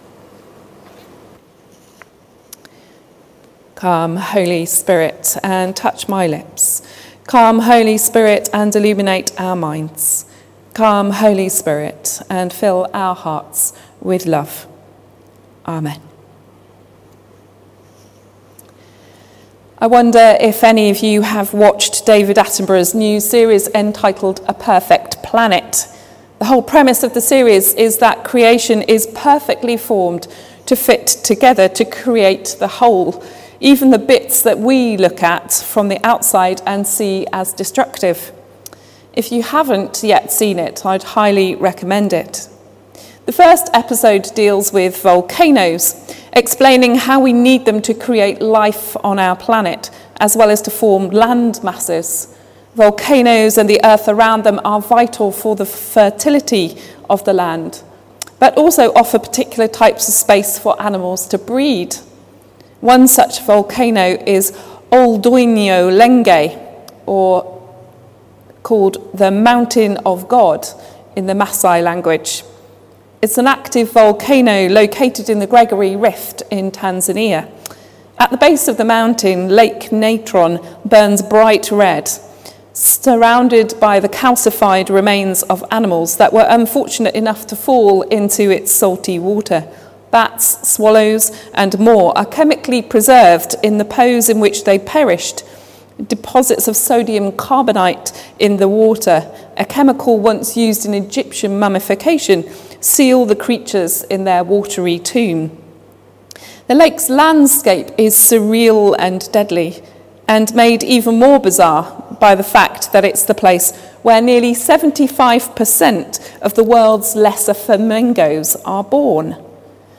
Sermon: Call And Response | St Paul + St Stephen Gloucester